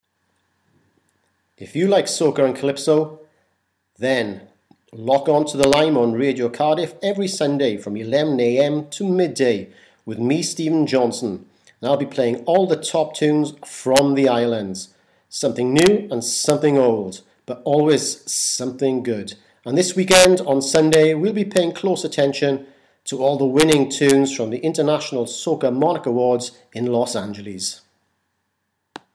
Plug for Radio Show